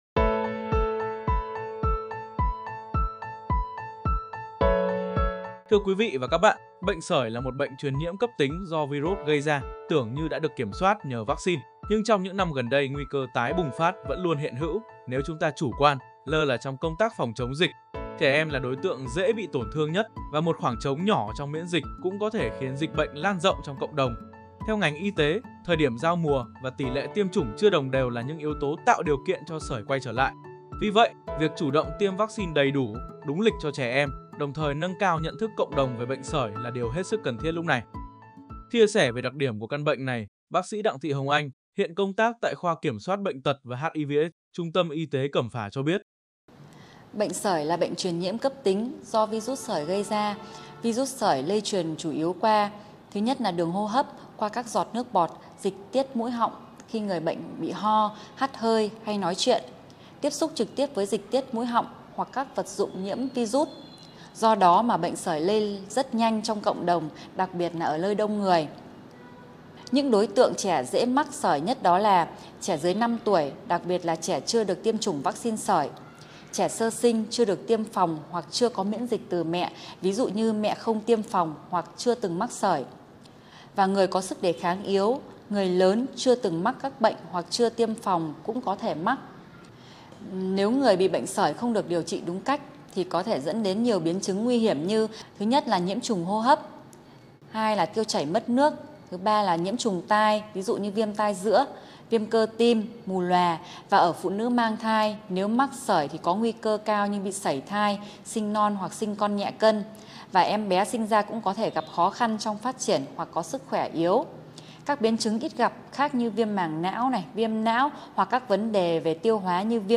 Ghi nhận thực tế công tác phòng, chống bệnh sởi của Trung tâm Y tế Cẩm Phả, mời quý vị và các bạn cùng theo dõi phóng sự sau đây.
0608.-Phat-thanh-soi-TTYT-Cam-Pha.mp3